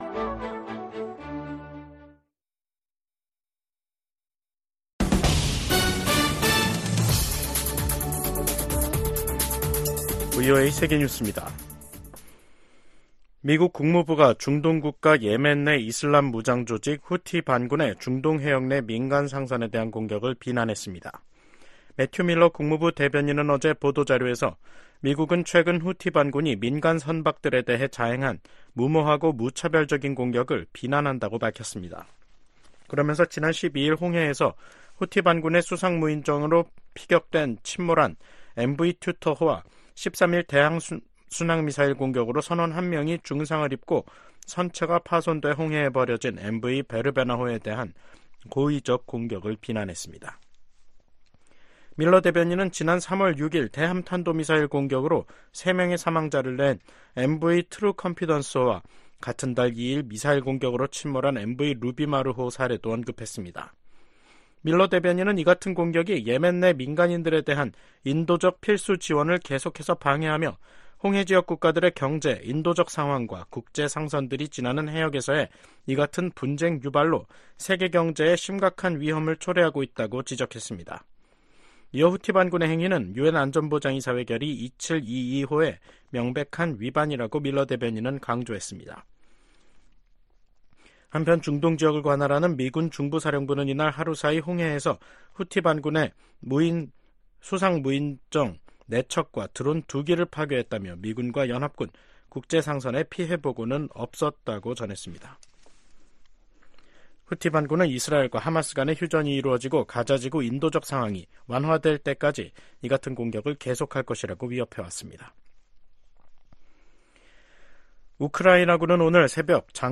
VOA 한국어 간판 뉴스 프로그램 '뉴스 투데이', 2024년 6월 21일 2부 방송입니다. 북한과 러시아가 군사협력 조약을 체결한 데 대해 미국 백악관과 국무부, 국방부등이 우려를 나타냈습니다. 국무부는 우크라이나에 대한 한국의 무기 지원 여부는 한국이 결정할 사안이라고 말했습니다. 한국 정부는 북한과 동맹에 준하는 조약을 체결한 러시아를 규탄하고 우크라이나에 살상무기를 지원하지 않는다는 기존 방침을 재검토하기로 했습니다.